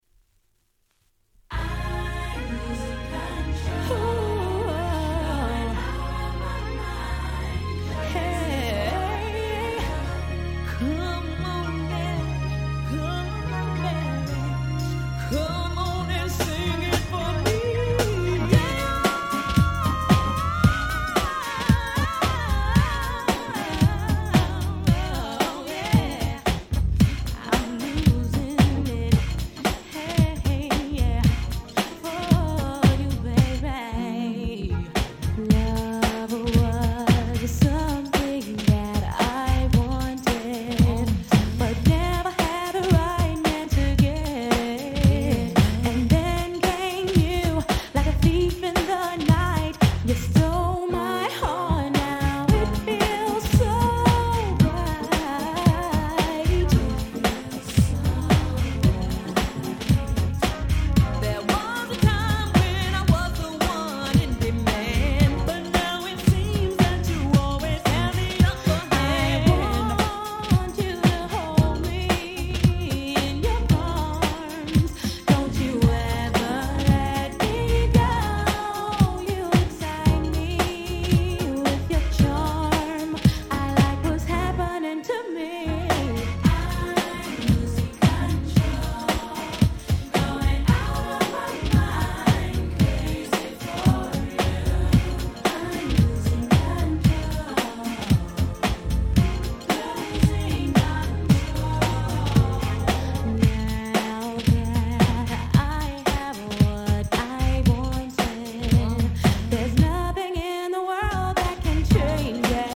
【Media】Vinyl 12'' Single